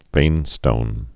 (vānstōn)